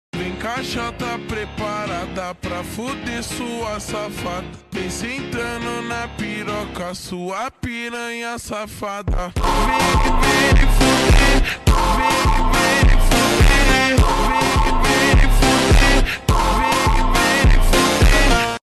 slowed (death sound effect)